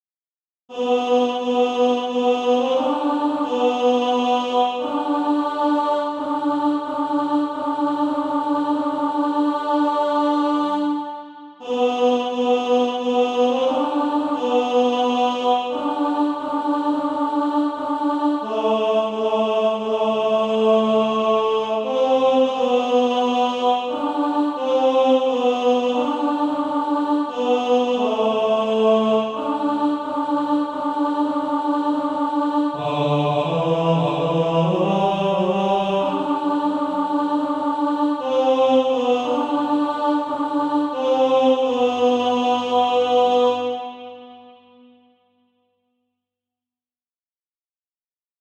Tenor Track.